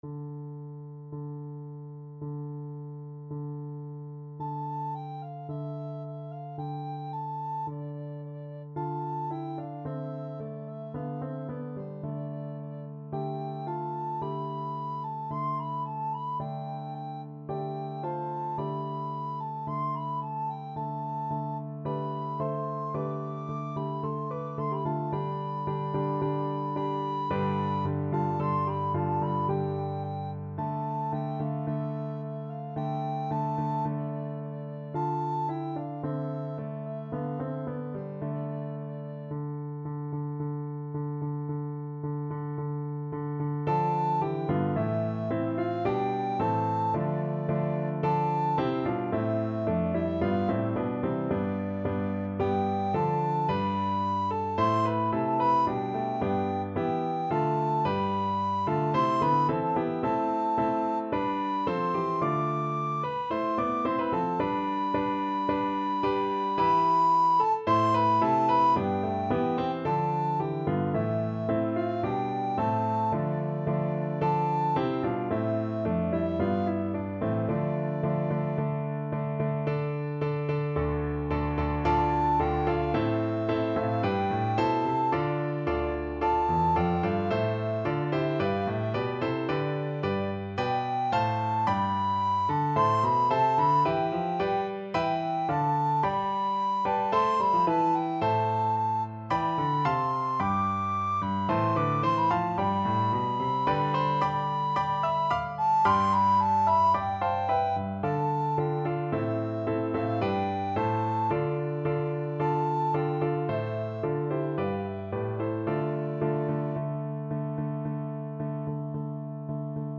Free Sheet music for Soprano (Descant) Recorder
C major (Sounding Pitch) (View more C major Music for Recorder )
2/2 (View more 2/2 Music)
Steady two in a bar =c.110
Classical (View more Classical Recorder Music)